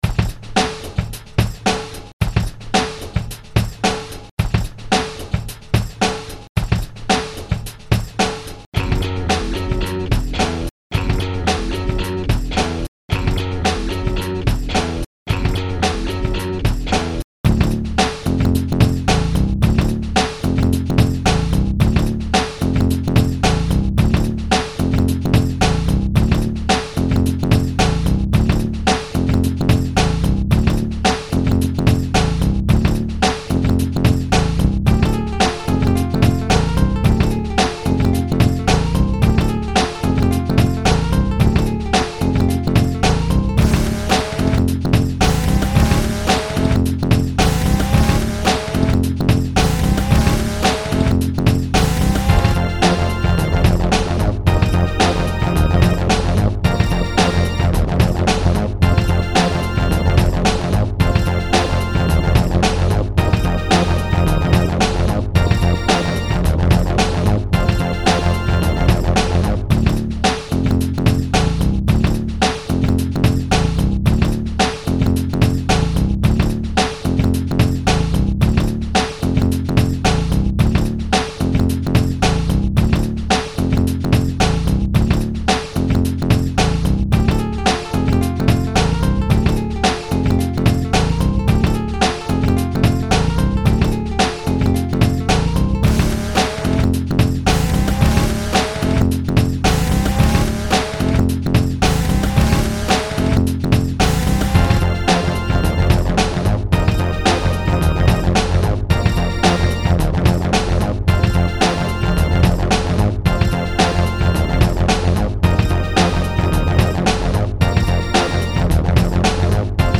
Instrumental -